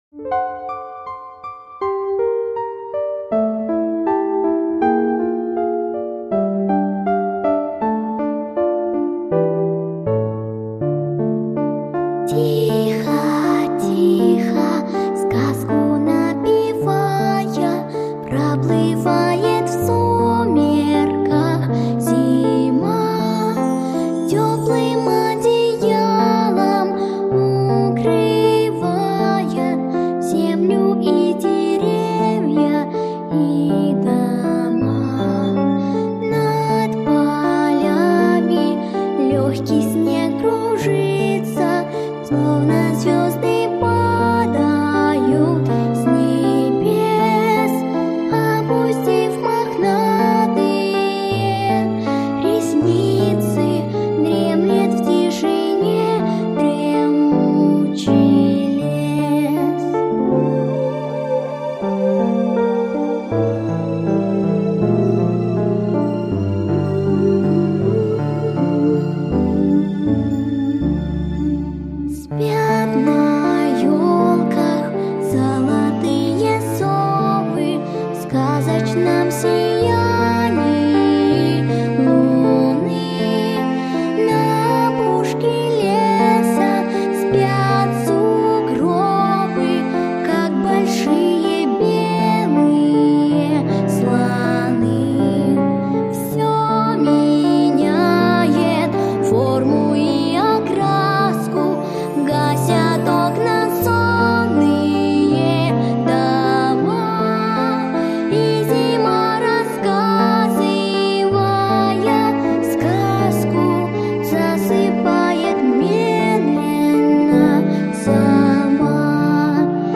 Песенки про зиму